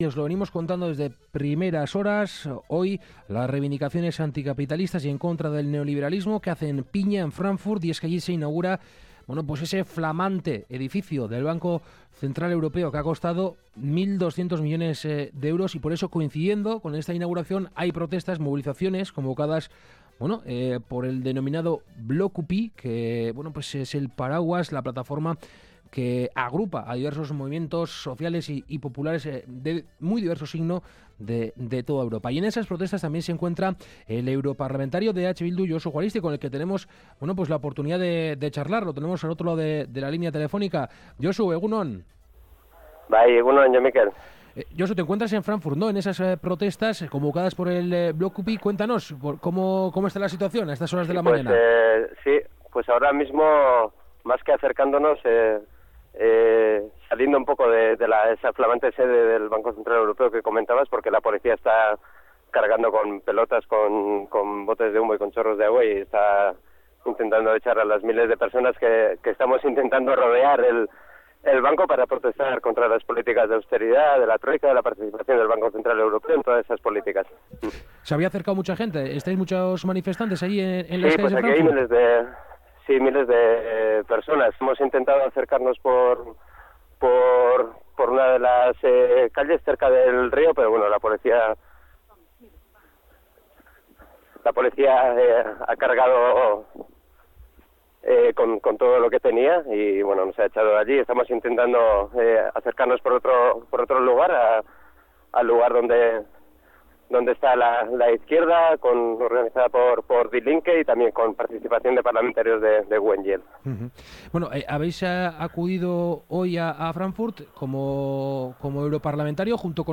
Entrevista con Josu Juaristi [EH Bildu] desde Fráncfort, entre barricadas y cargas policiales
Las protestas convocadas por el movimiento anticapitalista “Blockupy” con motivo de la inauguración de la nueva sede del BCE han derivado en enfrentamientos en Fráncfort, en medio de un gran despliegue de unidades antidisturbios. Unas movilizaciones en las que participa Josu Juaristi, parlamentario de EH Bildu. A primera hora de la mañana hemos tenido la oportunidad de entrevistarle.